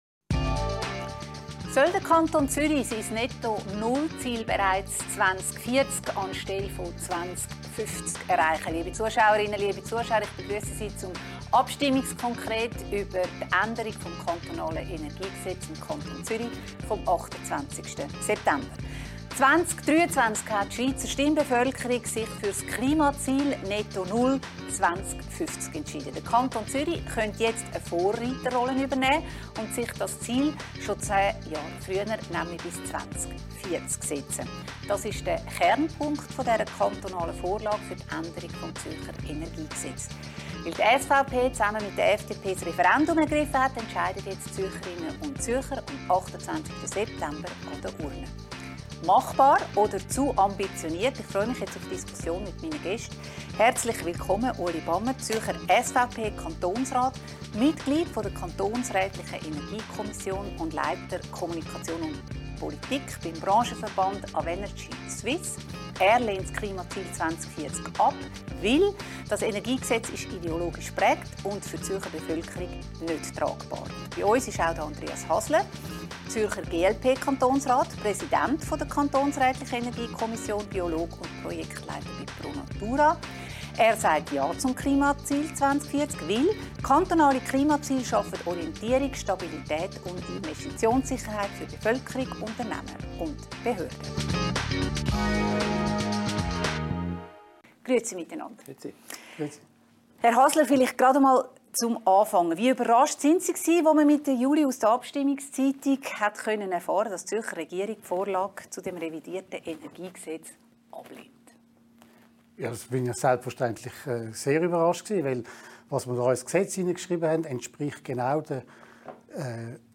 im Gespräch mit den beiden Zürcher Kantonsräten Andreas Hasler, GLP und Ueli Bamert, SVP.